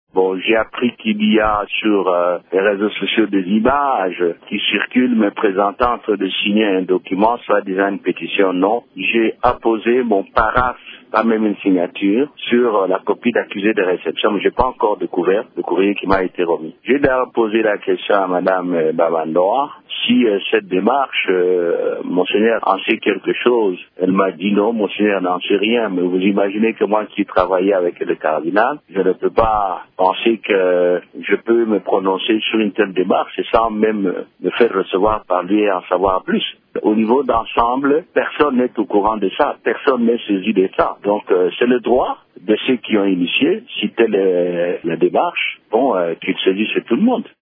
Vous pouvez écouter le député Christophe Lutundula: